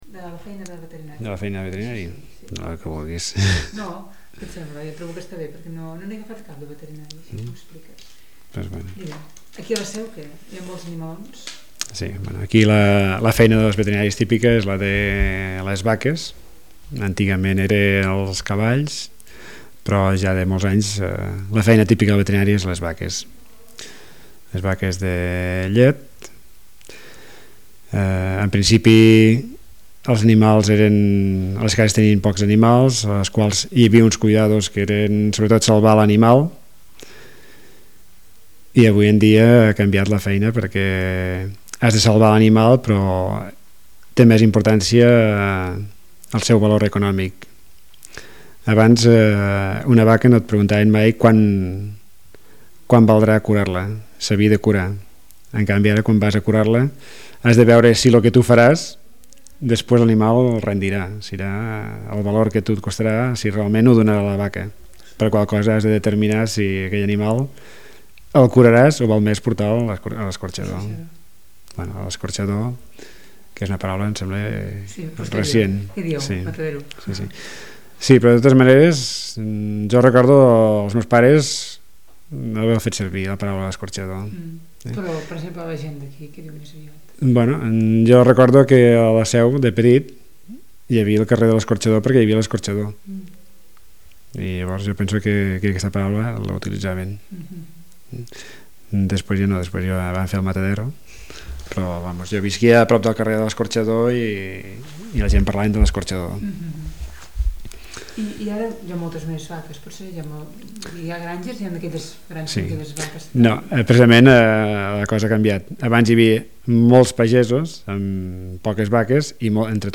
Aquest document conté la transcripció fonètica, la fonoortogràfica i l'arxiu de so d'un fragment de conversa lliure amb un informant de la Seu d'Urgell que forma part del Corpus Oral Dialectal (COD).
Linguistics, Spoken Catalan, North Western Catalan, Dialectology